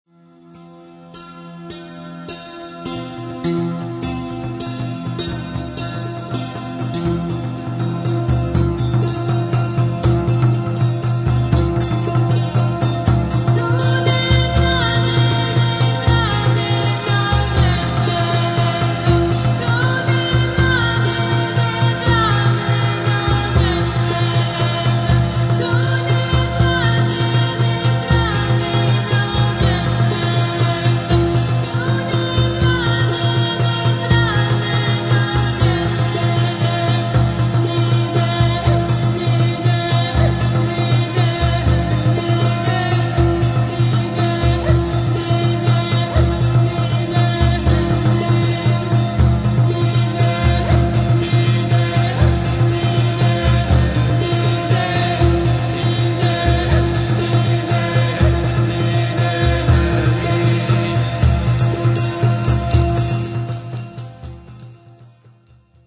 Heavenly voices and ethnical melodies!